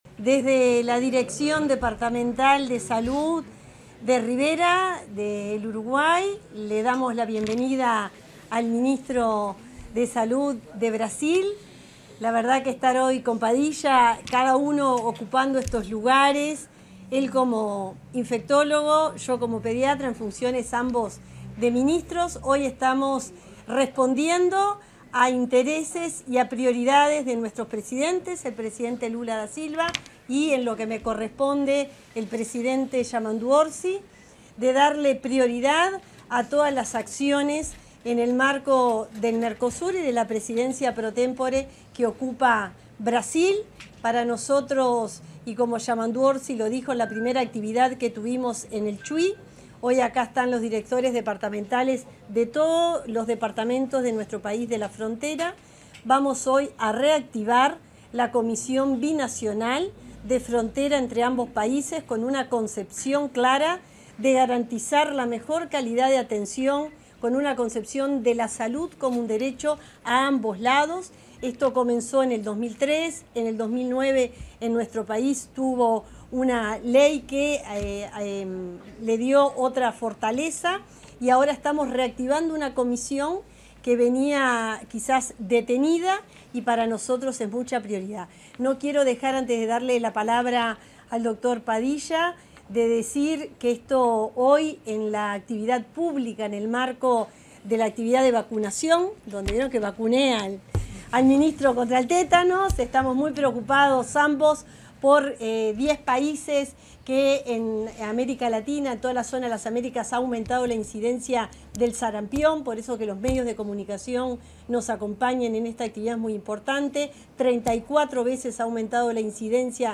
Declaraciones de la ministra de Salud Pública, Cristina Lustemberg